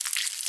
Minecraft Version Minecraft Version 1.21.5 Latest Release | Latest Snapshot 1.21.5 / assets / minecraft / sounds / mob / silverfish / step4.ogg Compare With Compare With Latest Release | Latest Snapshot